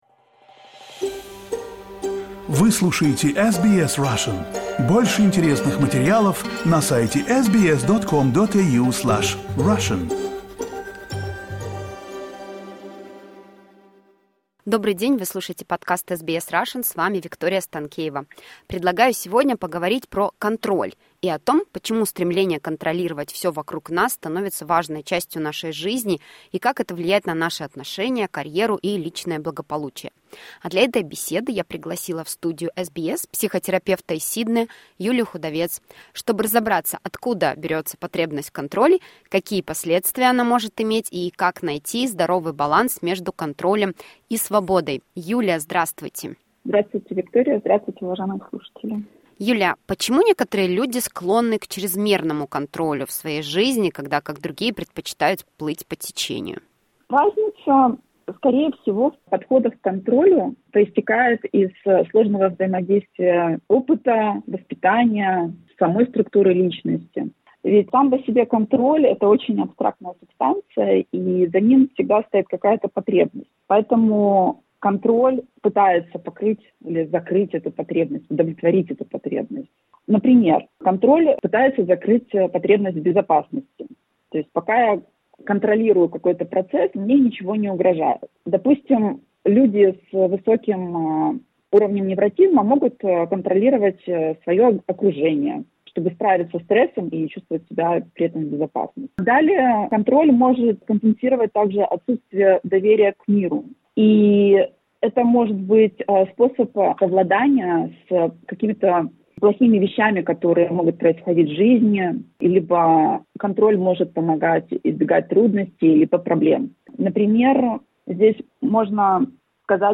SBS Russian поговорили с психотерапевтом из Сиднея